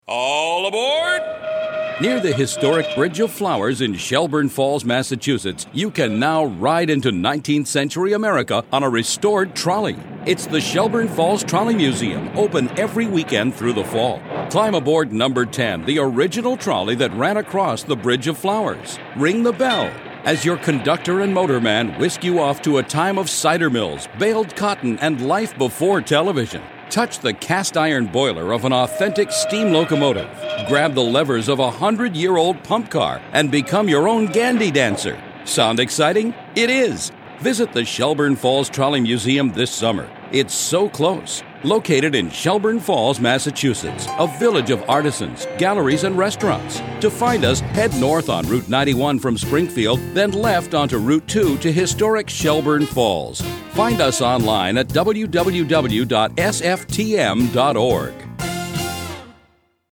Listen to a PSA developed for us by Goff Media. Permission is granted to air this sixty-second PSA on any radio station.